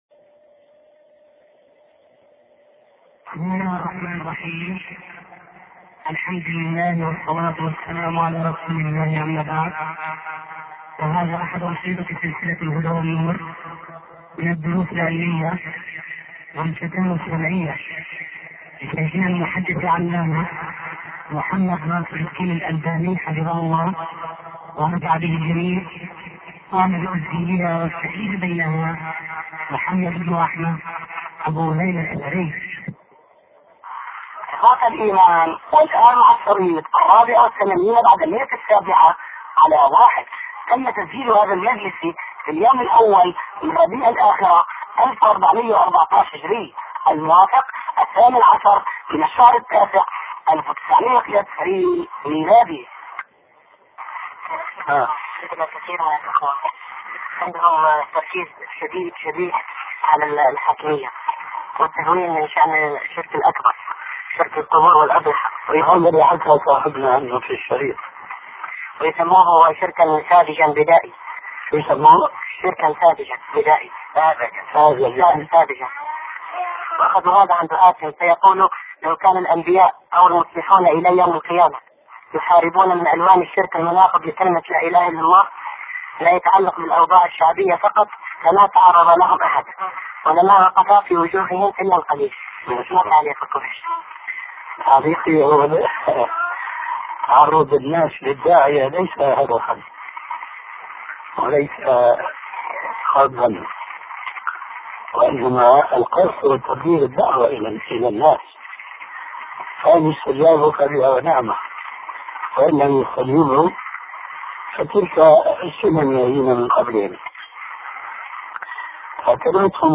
شبكة المعرفة الإسلامية | الدروس | الحق أحق أن يتبع |محمد ناصر الدين الالباني